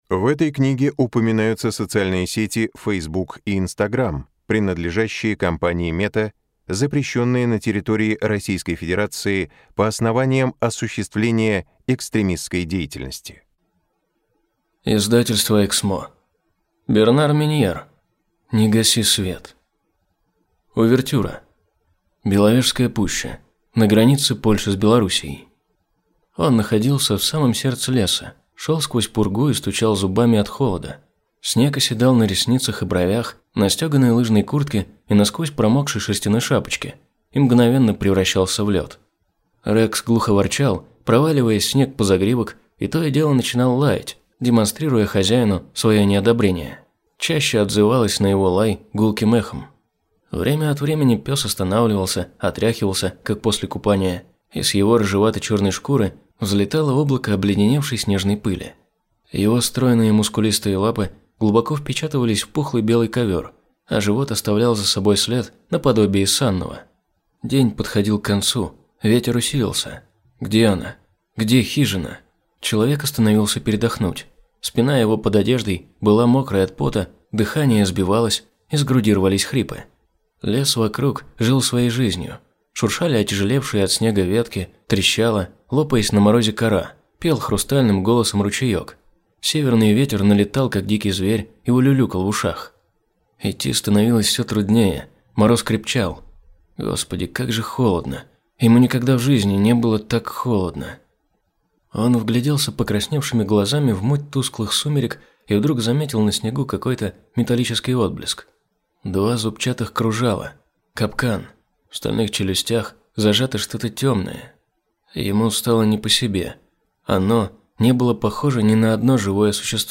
Аудиокнига Не гаси свет | Библиотека аудиокниг